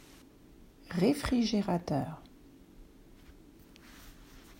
6. Réfrigérateur: Kühlschrank (reefrischeeratör)